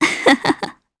Valance-Vox_Happy2_jp_b.wav